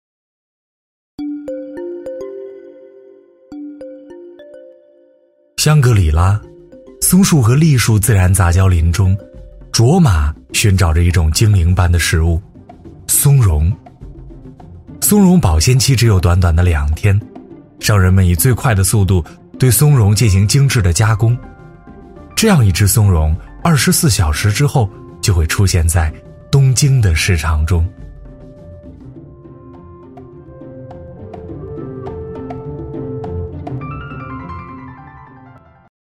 舌尖-男3-舌尖上的中国 +舒缓讲述美食 +香格里拉松茸.MP3